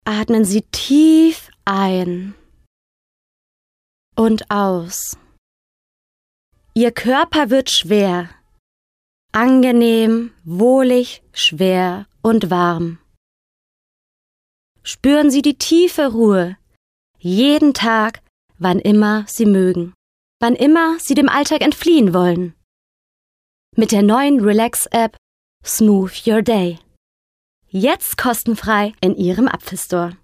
Sprecherin, Werbesprecherin